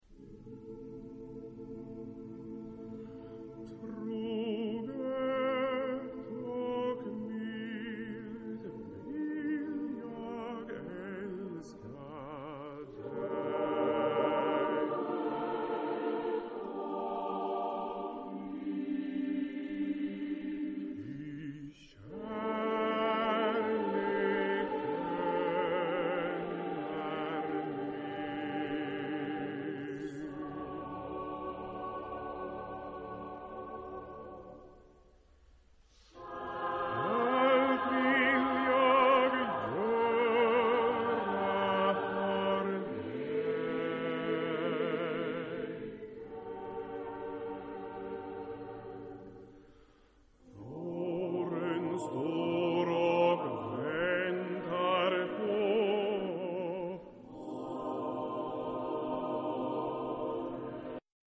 Chorgattung: SATB  (4 gemischter Chor Stimmen )
Solisten: Solo